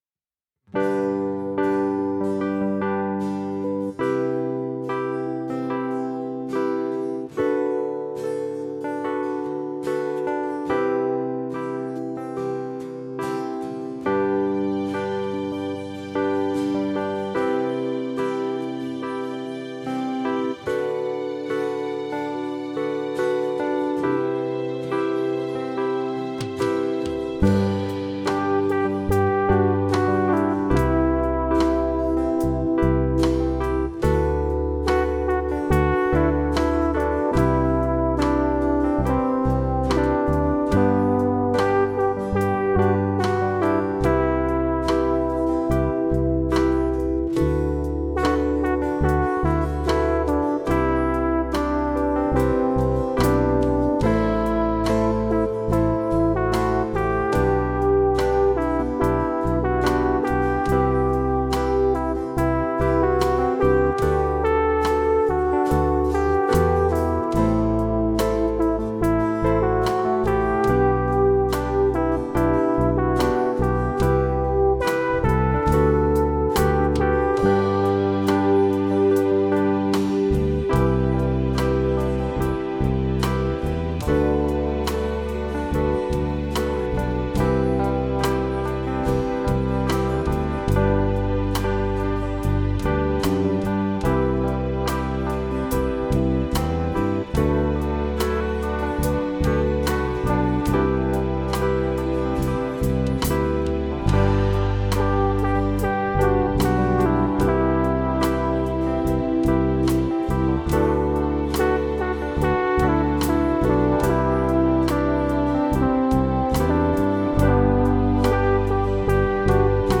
Gentle Groove
Laid-back groove with electric piano. Gets quite big at the end.
gentle.mp3